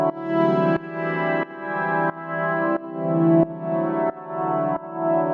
GnS_Pad-dbx1:4_90-E.wav